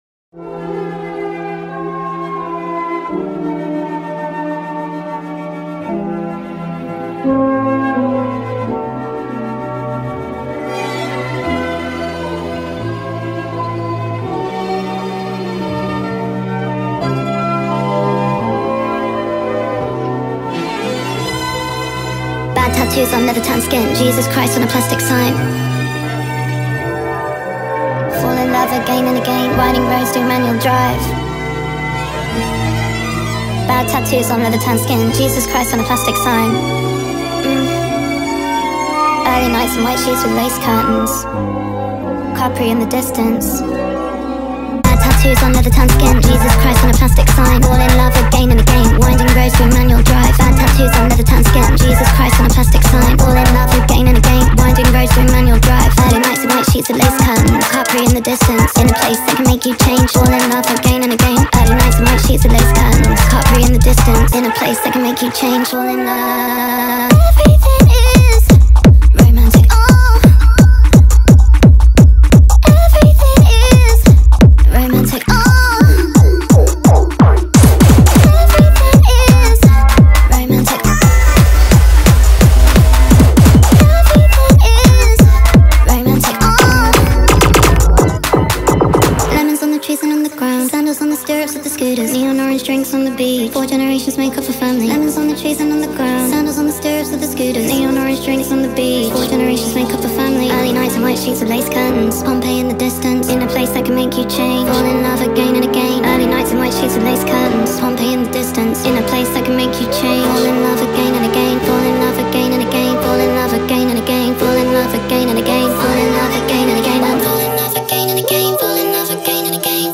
ورژن Sped Up و سریع شده